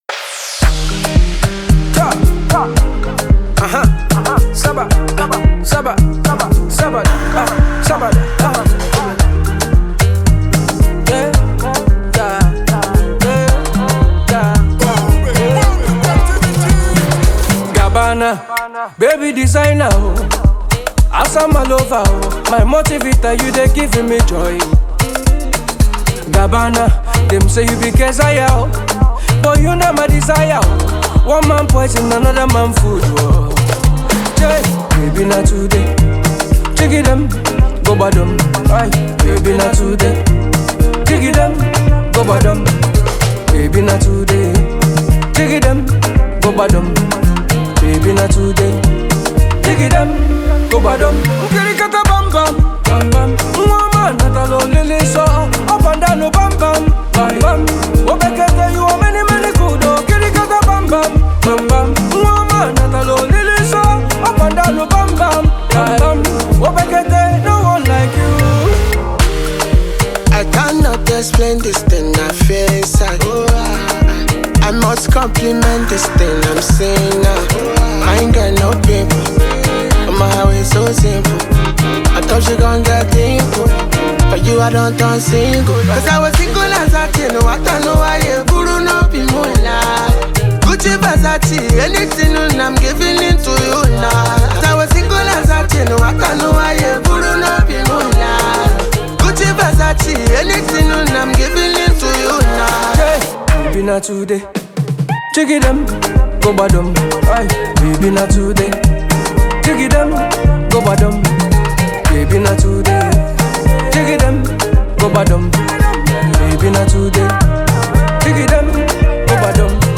A catchy song
highlife